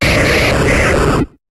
Cri d'Électrode dans Pokémon HOME.